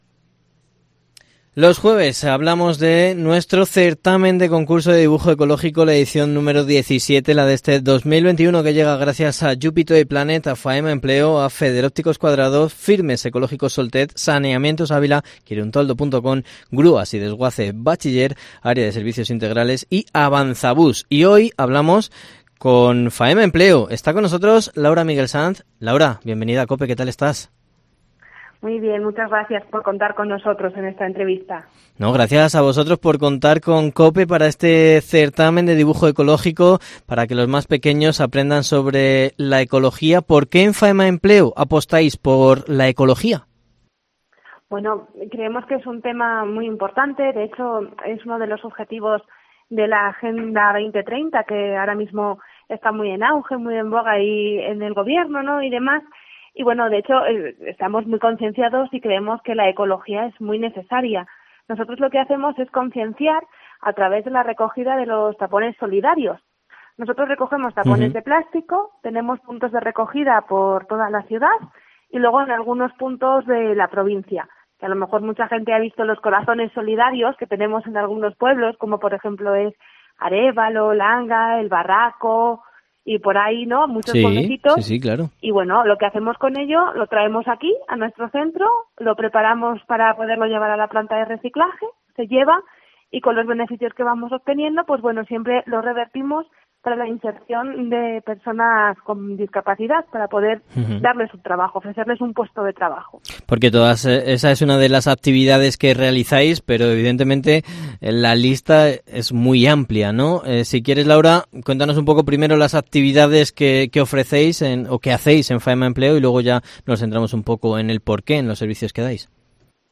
Entrevista con Faema Empleo en COPE Ávila